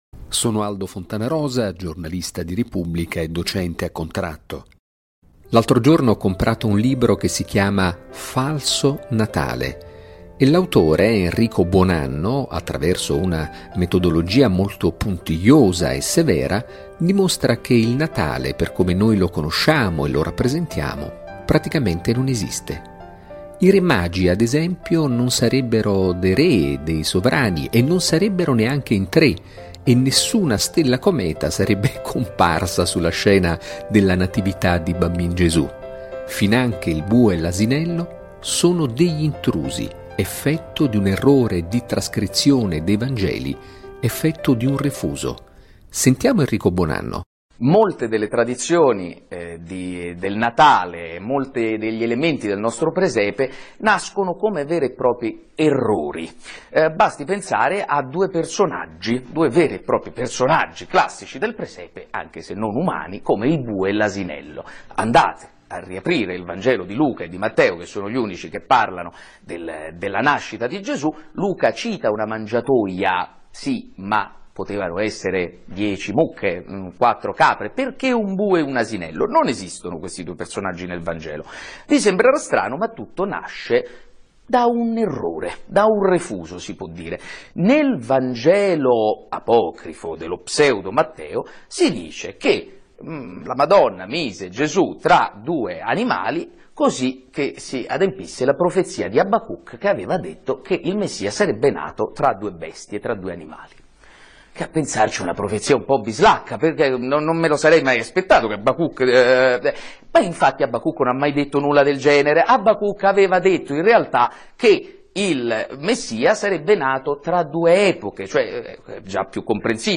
* (Brani musicali.